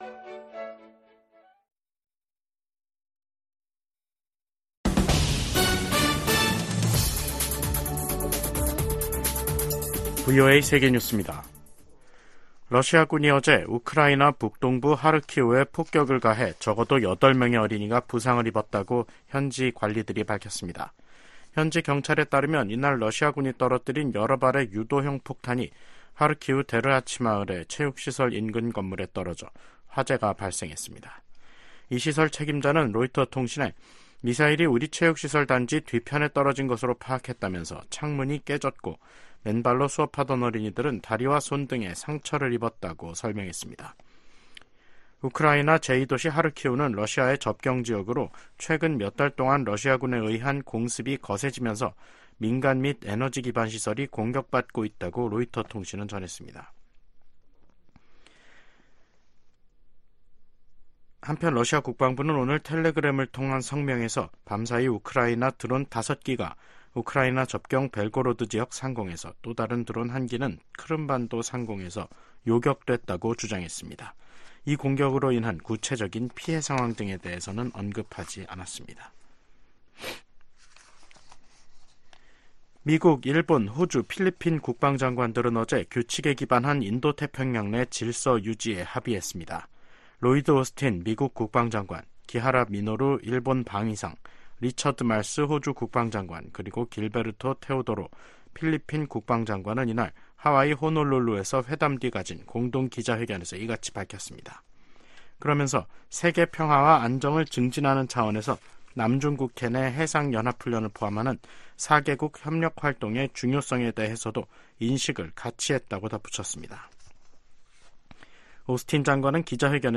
VOA 한국어 간판 뉴스 프로그램 '뉴스 투데이', 2024년 5월 3일 2부 방송입니다. 러시아가 올들어 지금까지 유엔 안보리가 정한 연간 한도를 넘는 정제유를 북한에 공급했다고 백악관이 밝혔습니다. 북한 해커들이 대북 정책 전략이나 관련 정보를 수집하기 위해 미 정부 당국자나 전문가에게 위장 이메일을 발송하고 있어 주의해야 한다고 미국 정부가 경고했습니다.